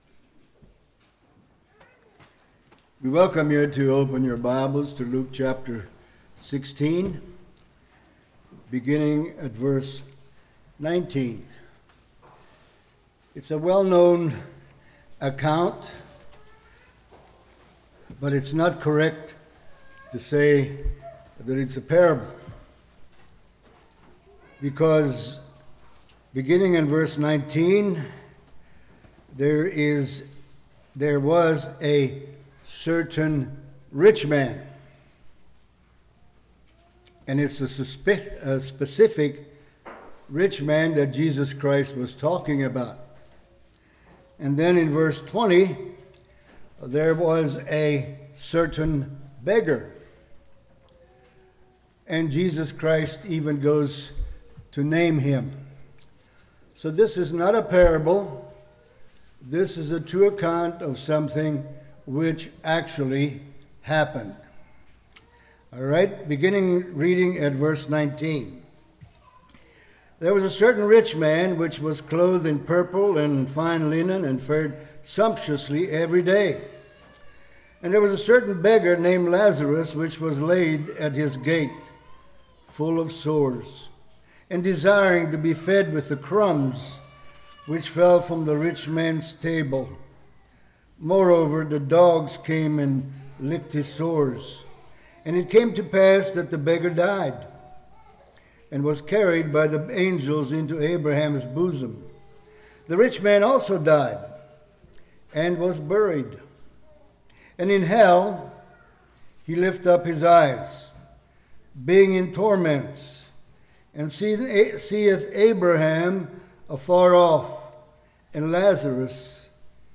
Luke 16:19-31 Service Type: Morning Satan Gets People to Question God The Reality of Hell How Terrible is Hell?